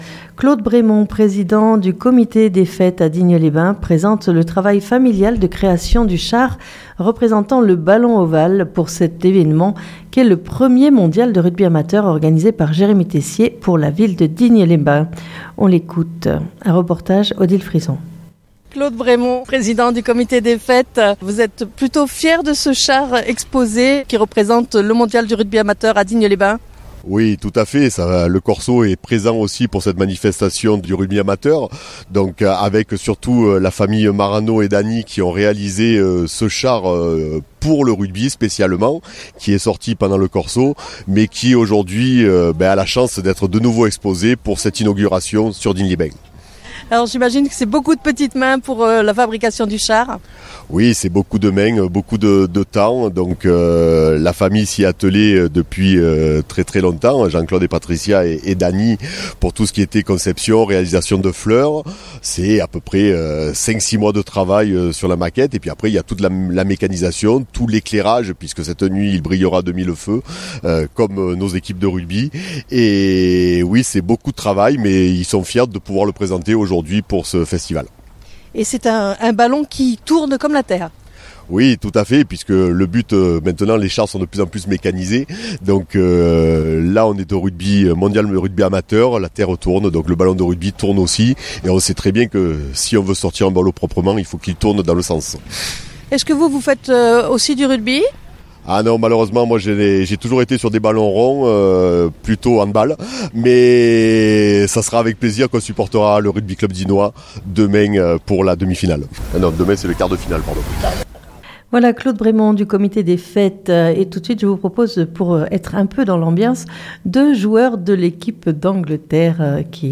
Vous entendrez également 2 joueurs de l'équipe représentant l'Angleterre et leur joie de pouvoir être à Digne les bains pour cet événement.